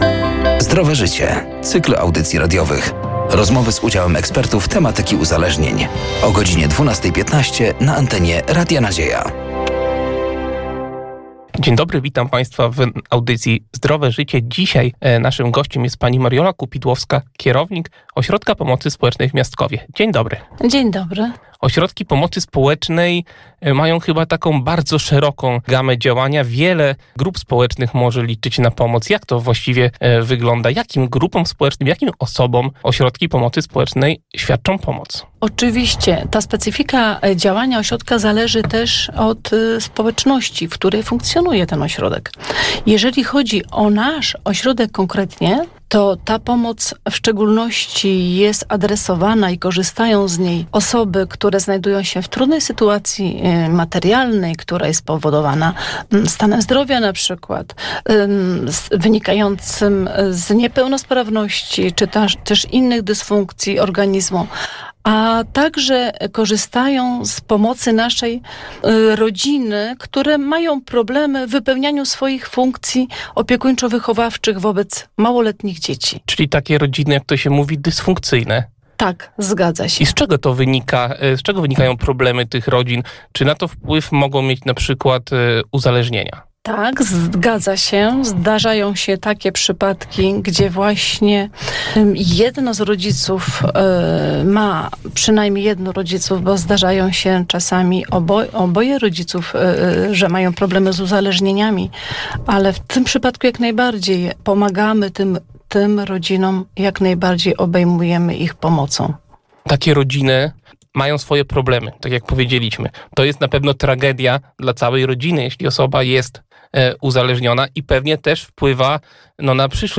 „Zdrowe Życie” to cykl audycji radiowych. Rozmowy z udziałem ekspertów tematyki uzależnień.